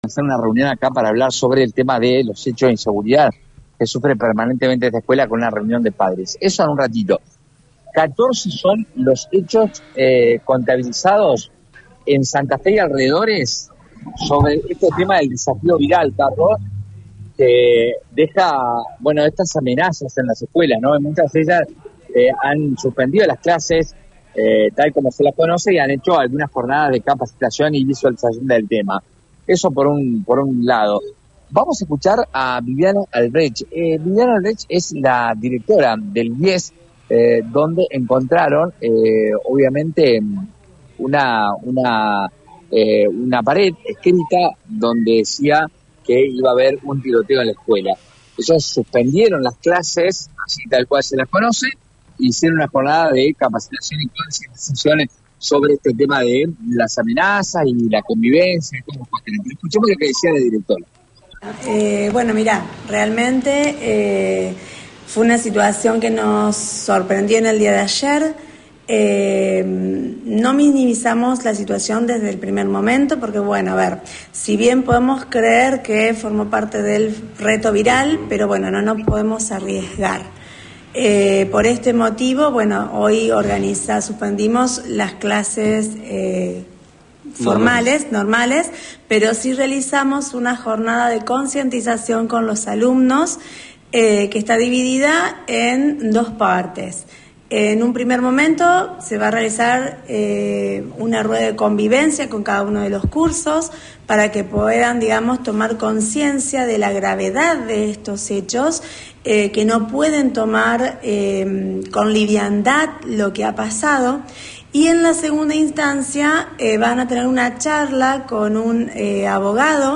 “Hay muchas cosas que pasaron a mayores: amenazas puntuales”, expresó un padre en diálogo con el móvil de EME.